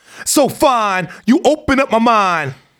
RAPHRASE16.wav